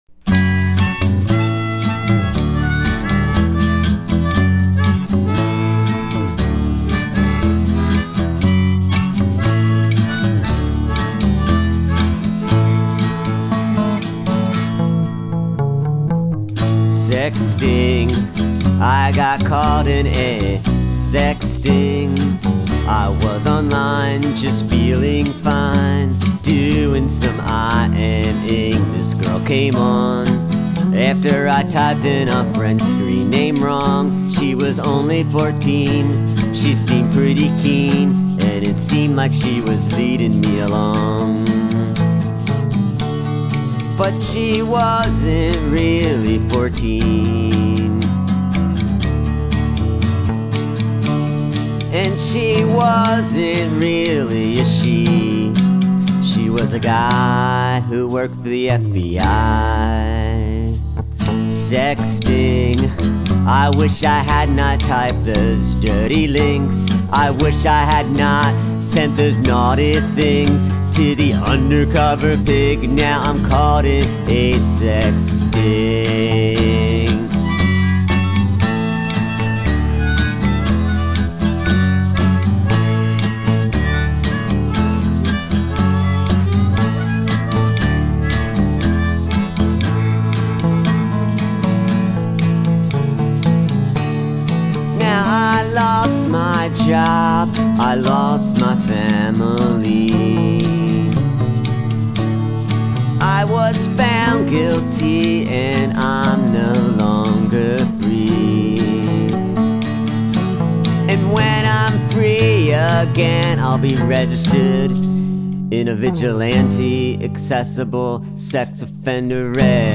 two demos